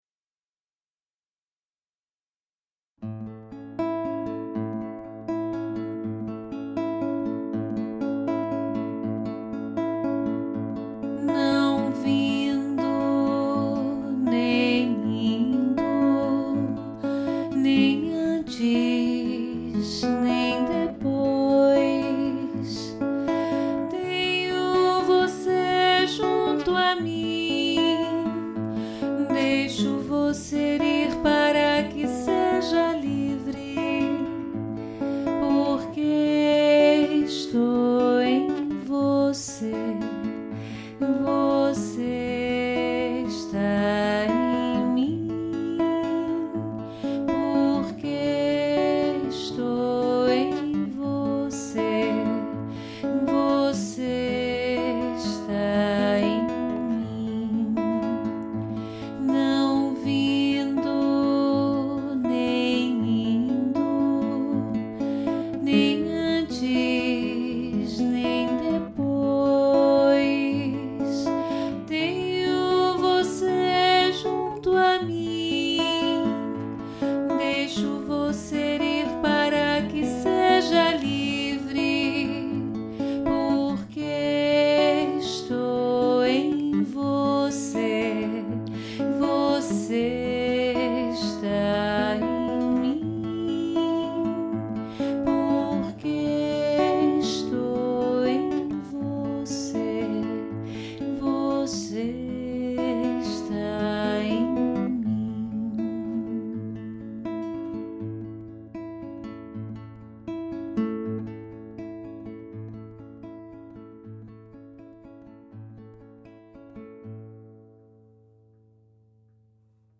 Voz
Violão